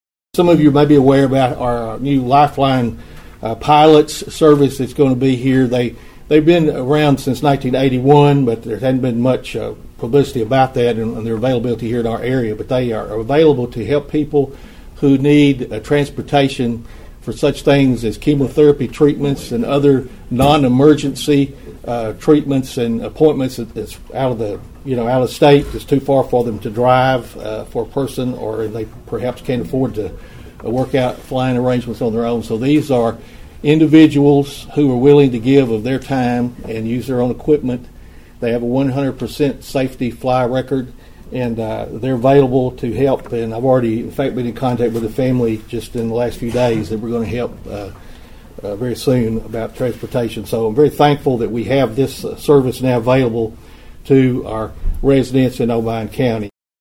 Mayor Carr also talked about the new flight program from Everett Stewart Regional Airport to assist area residents with long doctor visits and appointments.(AUDIO)